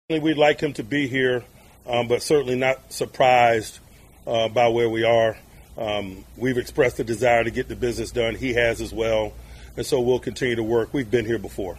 TJ Watt is not at the minicamp as his contract negotiations grinds on. Tomlin took it in stride.